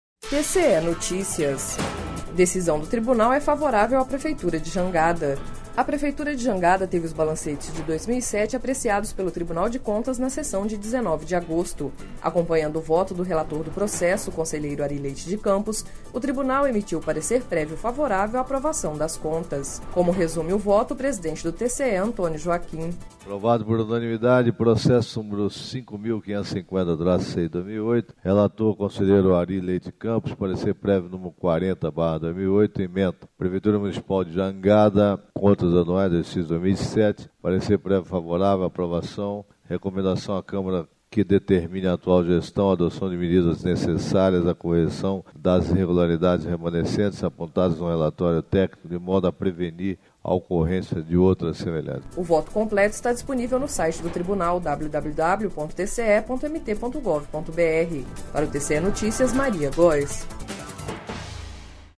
Sonora: Antonio Joaquim – presidente TCE-MT